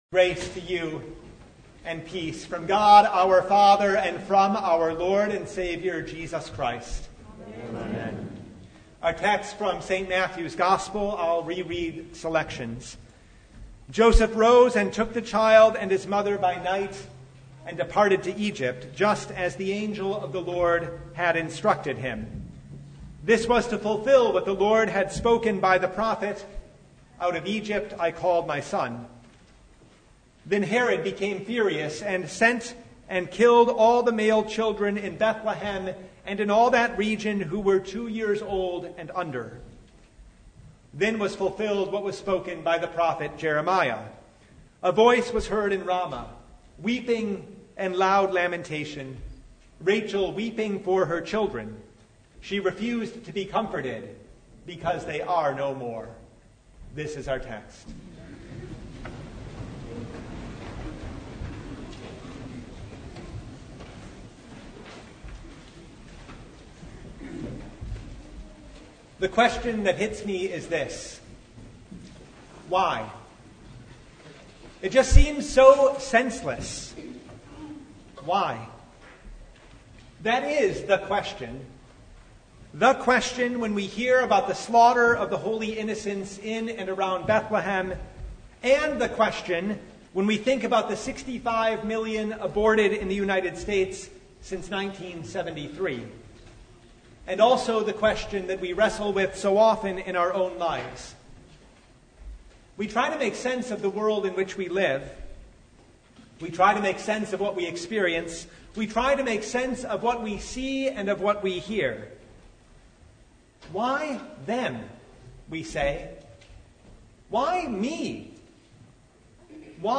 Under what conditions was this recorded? Passage: Matthew 2:8, 12-23 Service Type: The Feast of the Holy Innocents